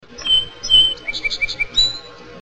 The Diversity of Song Sparrow Songs
All songs were sung by a single individual male Song Sparrow over a period of 2-3 hours. Each song differs in its cadence, phrases (single notes, trills), or sequence of phrases.